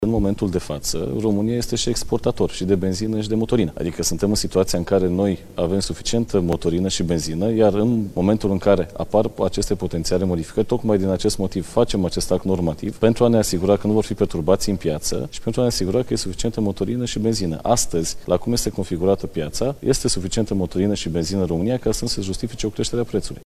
Ministrul Energiei, Bogdan Ivan, anunță că prețul combustibilului la pompă nu ar crește cu mai puțin de 10 zile înainte ca operațiunile companiei Lukoil să fie oprite.